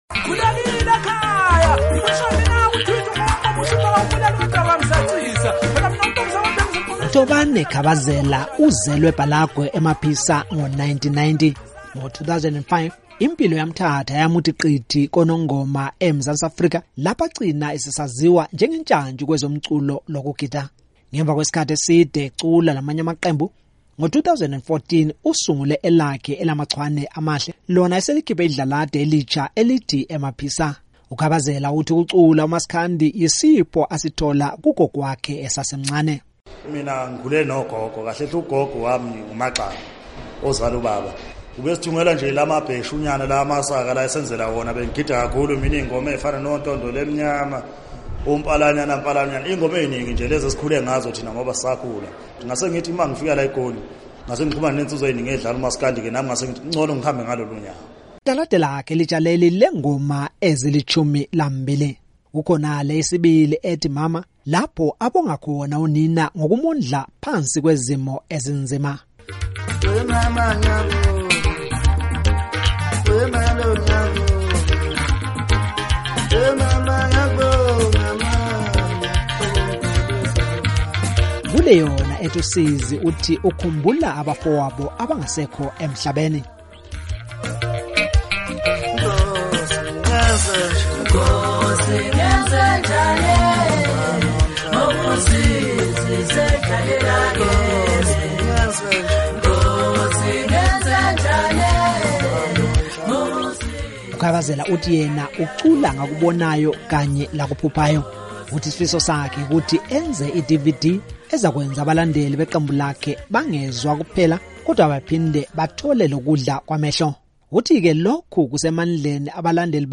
Iqembu loMculo kaMaskandi